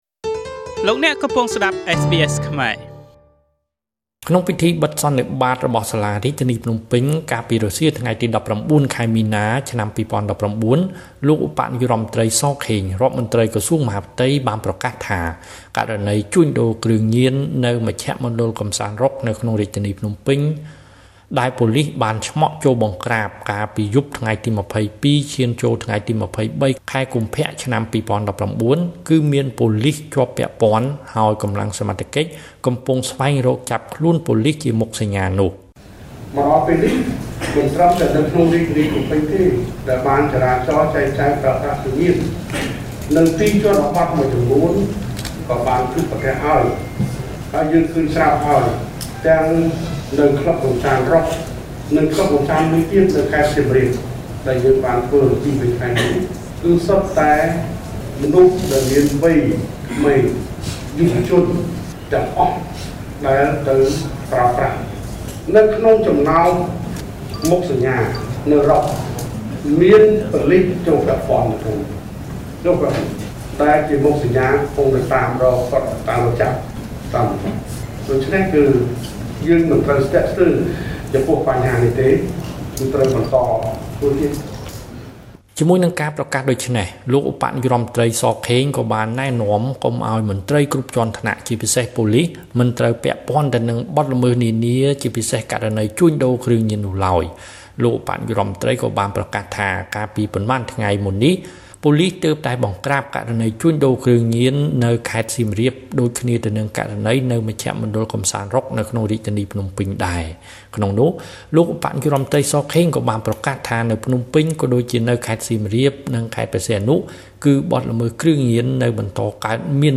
ក្នុងពិធីបិទសន្និបាតរបស់សាលារាជធានីភ្នំពេញ កាលពីរសៀលថ្ងៃទី១៩ ខែមីនា ឆ្នាំ២០១៩ លោកឧបនាយករដ្ឋមន្ត្រី ស ខេង រដ្ឋមន្ត្រីក្រសួងមហាផ្ទៃ បានប្រកាសថា ករណីជួញដូរគ្រឿងញៀន នៅមជ្ឈមណ្ឌលកម្សាន្ត រ៉ុក ក្នុងរាជធានីភ្នំពេញ ដែលប៉ូលិសបានឆ្មក់ចូលបង្ក្រាប កាលពីខែកុម្ភៈ ឆ្នាំ២០១៩ គឺមានប៉ូលិសជាប់ពាក់ព័ន្ធ ហើយកម្លាំងសមត្ថកិច្ច កំពុងស្វែងរកចាប់ខ្លួនប៉ូលិសនោះ។